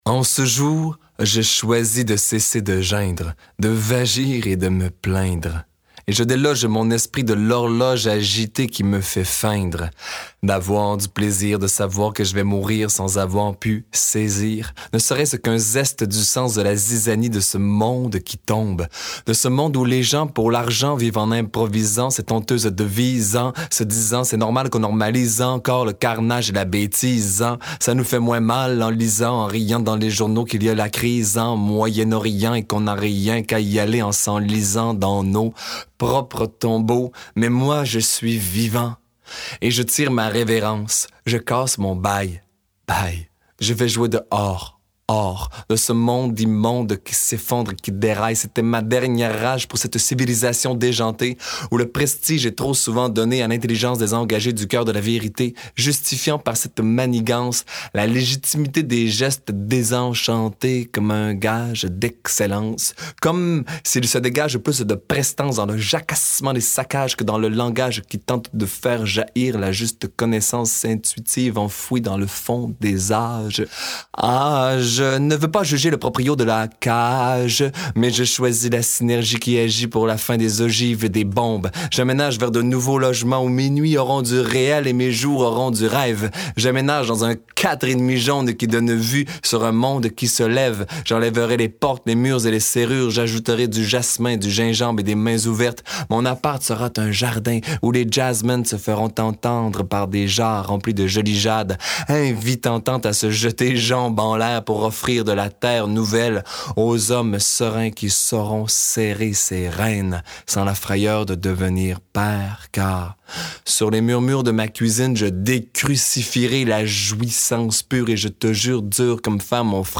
De facéties en histoires traditionnelles, de récits de vie en slam, découvrez les paysages variés et bien vivants de la littérature orale actuelle : vous y entendrez des histoires à ne pas mettre dans toutes les oreilles !